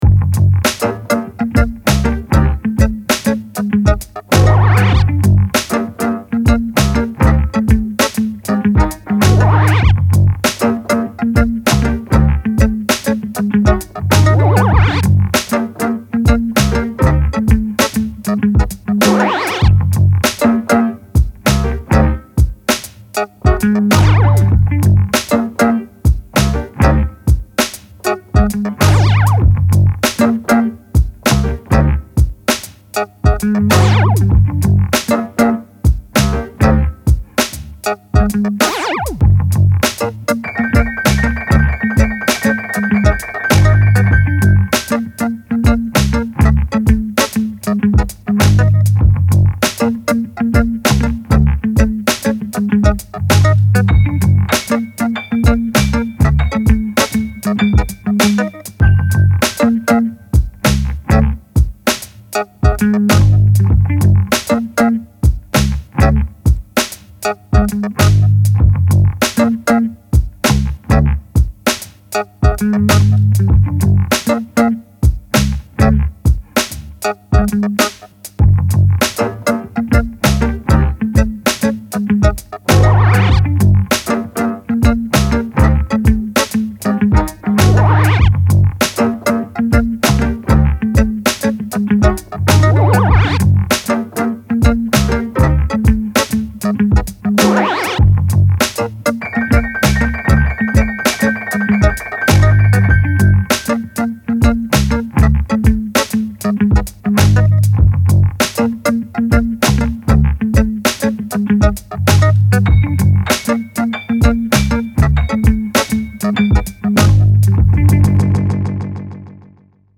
Fully loaded leftfield funk, lay back then jump up.
Funky odd hip hop beat with groovy attitude.